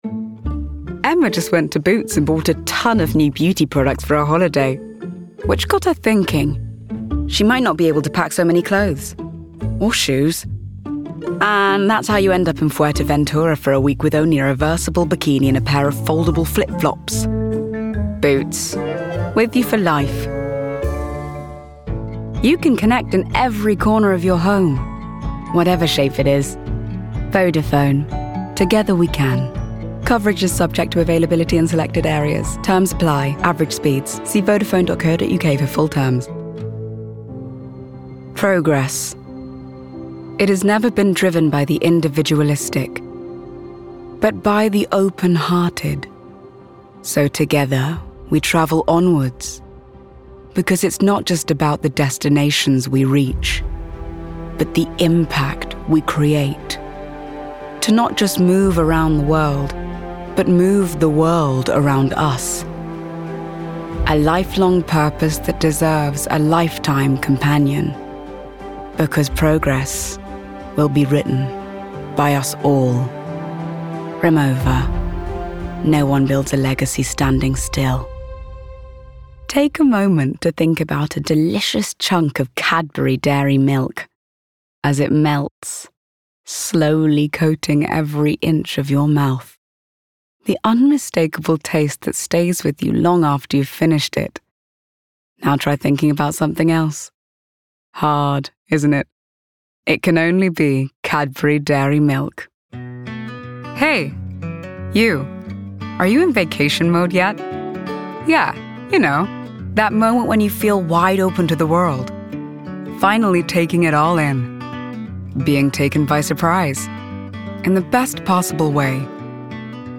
RP
Female
Assured
Husky
Rich
Smooth
Warm
COMMERCIAL REEL